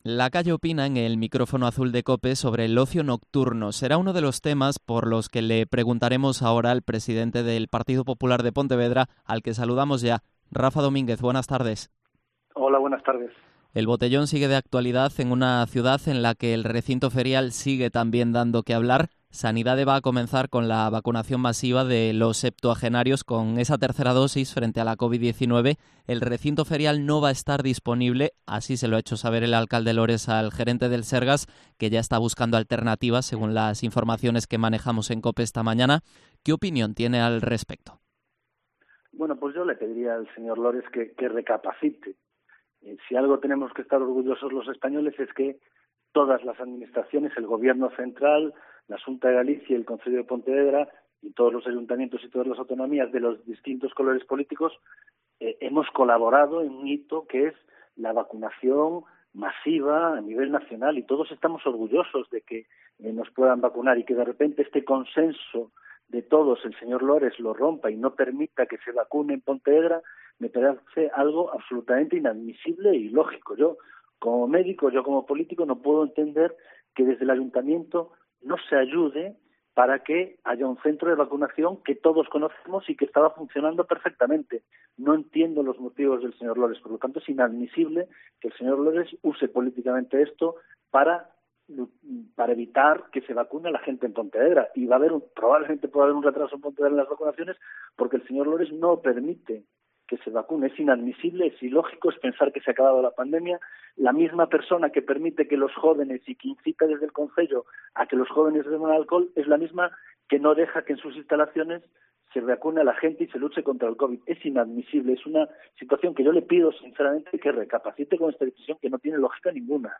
Entrevista a Rafa Domínguez, presidente del PP de Pontevedra